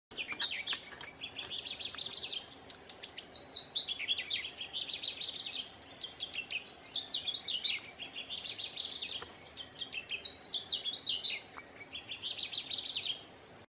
Two-banded Warbler (Myiothlypis bivittata)
Life Stage: Adult
Location or protected area: Parque Nacional Calilegua
Condition: Wild
Certainty: Recorded vocal